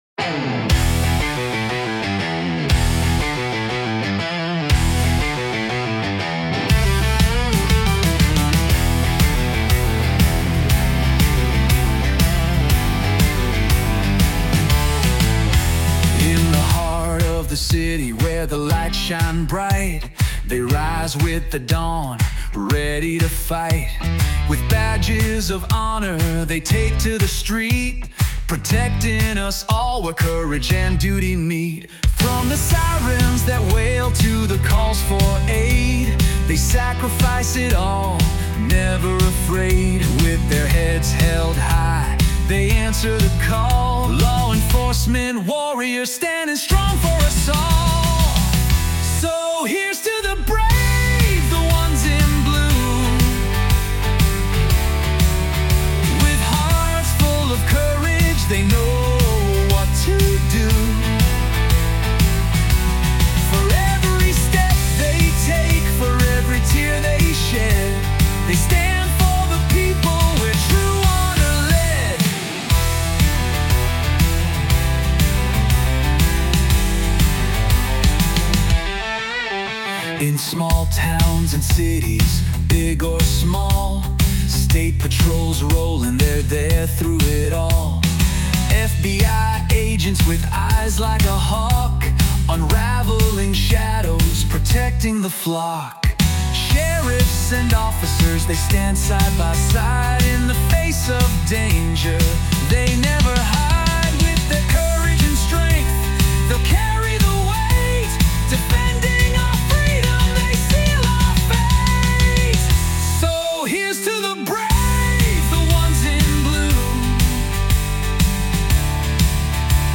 Patriotic Music